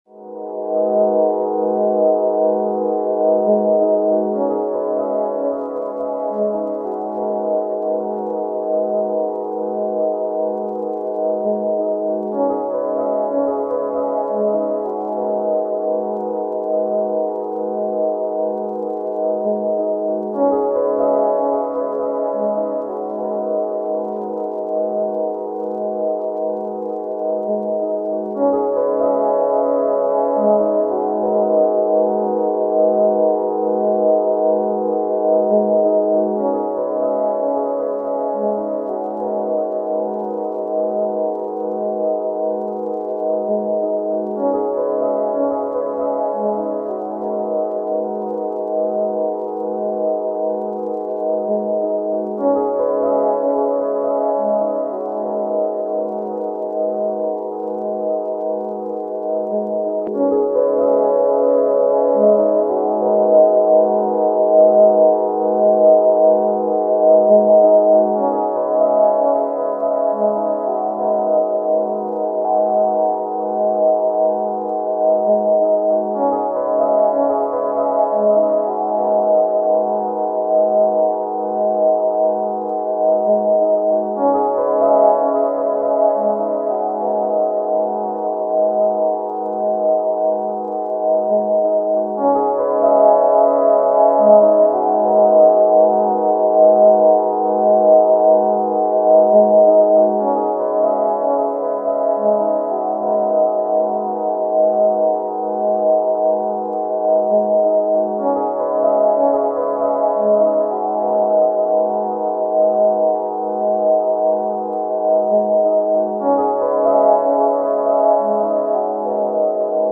demo strings
demo strings with VCF
demo square with VCF
demo TB303 wannabe
demo PCM drum kit demo